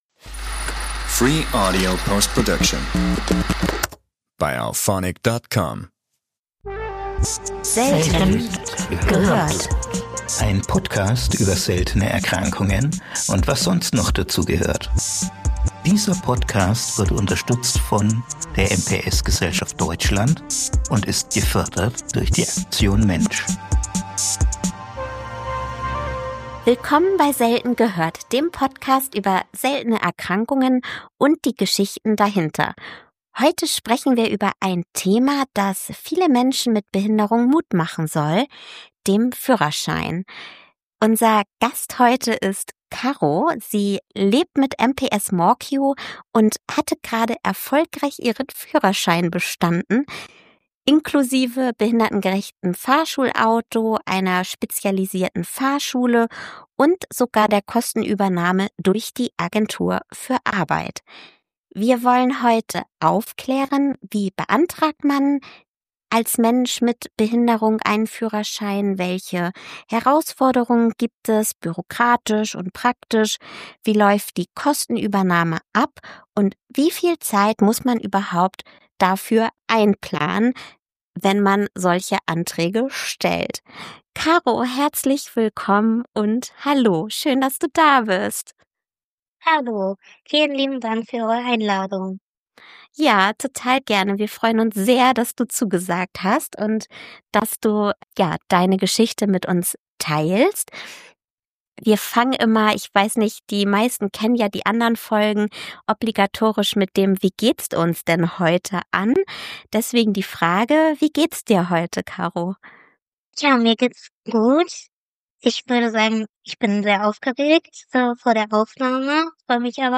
Bonus: Interview